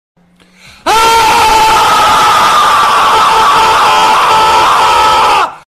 Low Quality Scream Sound Effect Free Download
Low Quality Scream